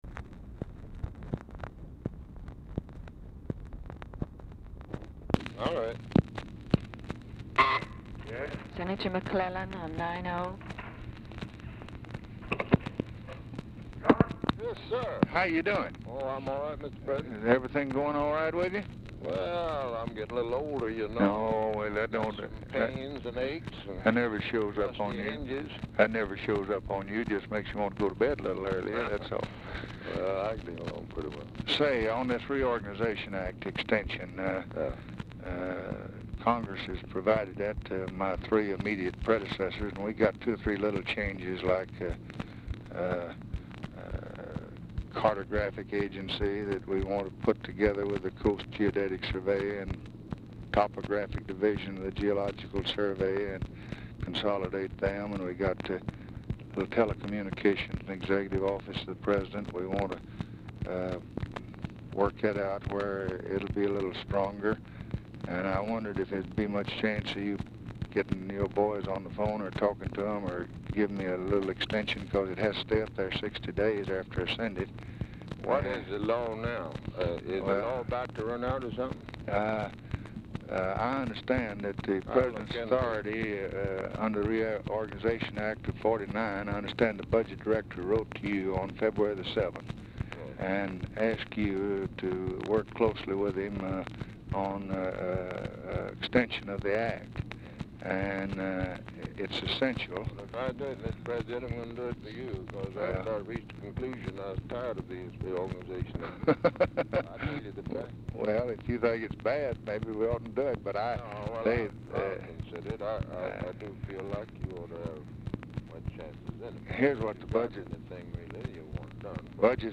Telephone conversation # 2633, sound recording, LBJ and JOHN MCCLELLAN, 3/24/1964, 11:55AM | Discover LBJ
Format Dictation belt
Location Of Speaker 1 Oval Office or unknown location
Specific Item Type Telephone conversation Subject Congressional Relations Federal Budget Legislation